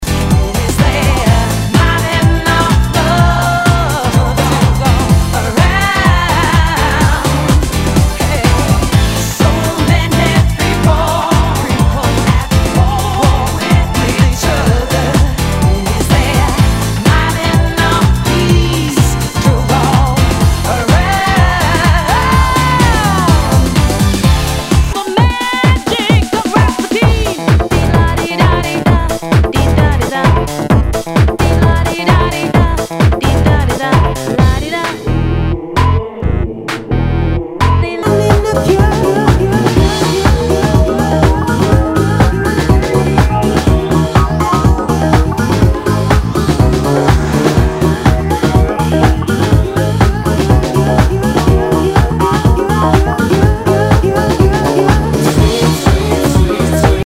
HOUSE/TECHNO/ELECTRO
ナイス！ヴォーカル・ハウス！